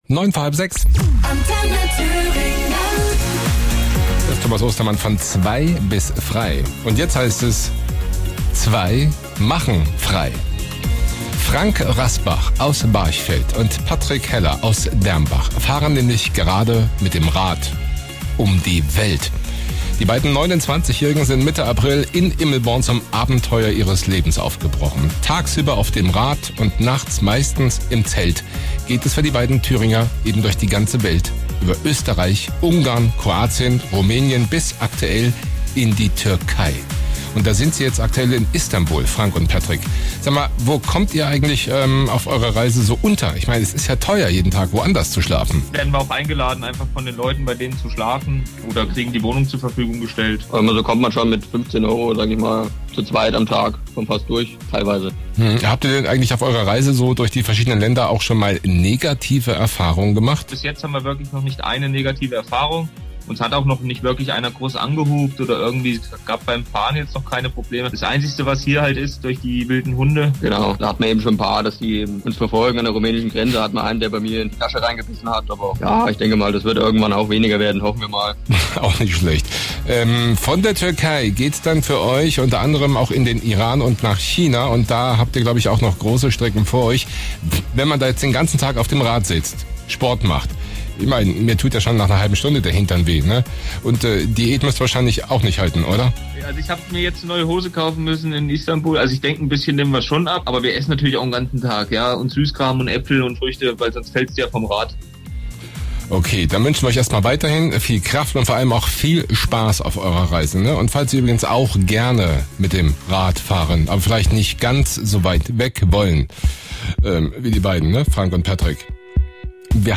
Unser Zusammenschnitt  lief bereits mehrmals in den Nachrichten im Radio.
Mitschnitt 4 wird Landesweit ausgestrahlt